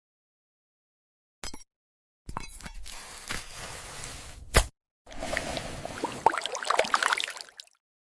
When a crystal watermelon reveals a secret koi pond inside… 🪷💧 A surreal ASMR slice of peace.